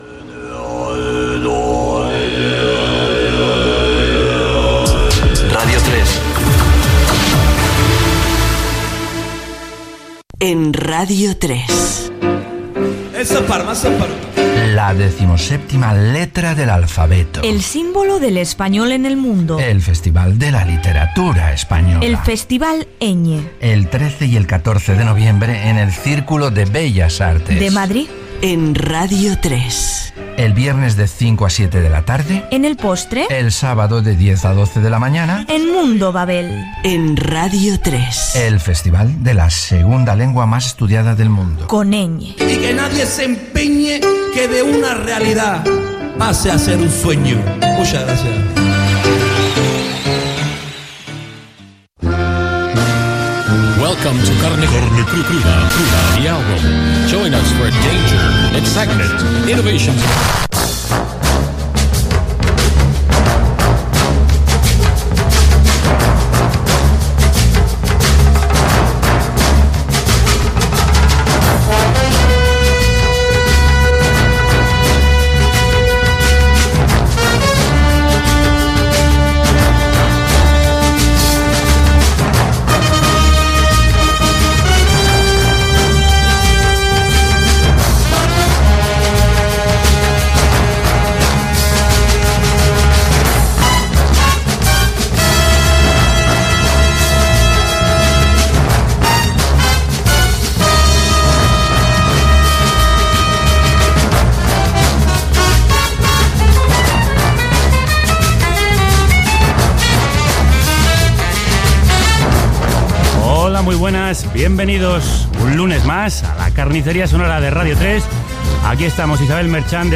Indicatiu de la ràdio, promoció del seguiment de Radio 3 del Festival Ñ . Sintonia, presentació, equip, el cas d'un jove ludòpata, titulars del dia del portal "El mundo today", novetat musical
Entreteniment
FM